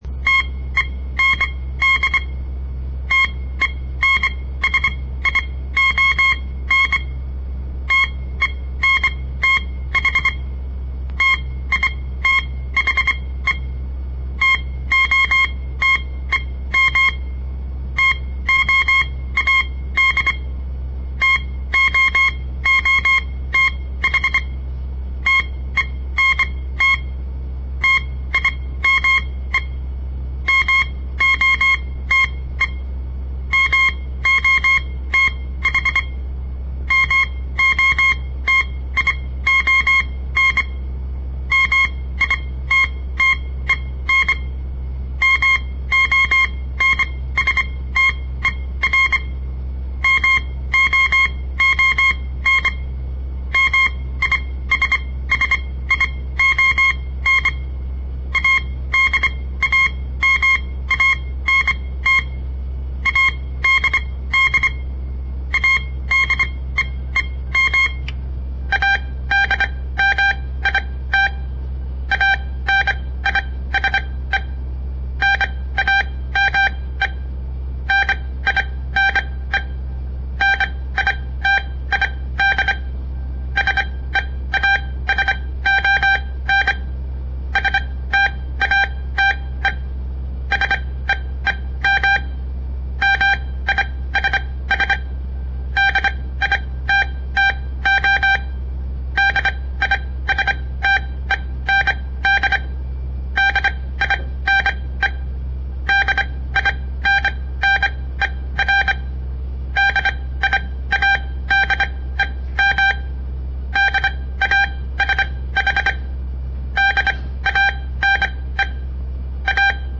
On the air Morse code practice from the Southern Montana Amatuer Radio Association; all code, no read back
Code_Practice-02.mp3